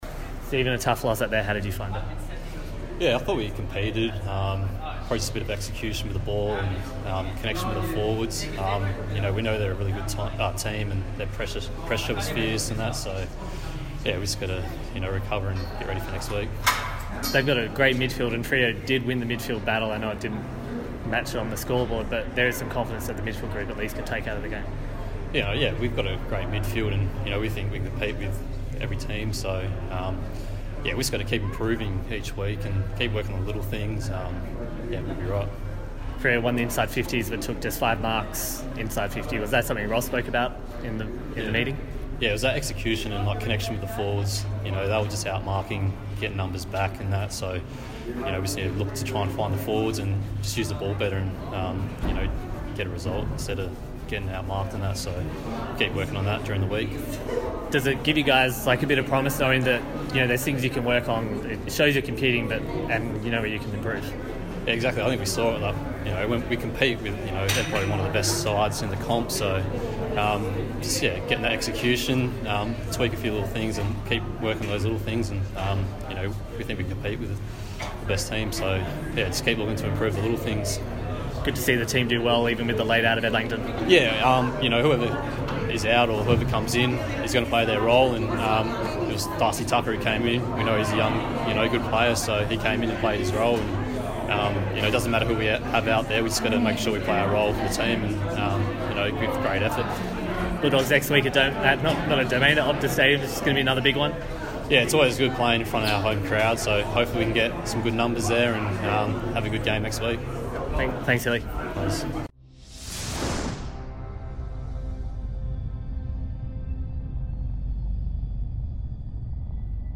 Stephen Hill post-match interview - Round 4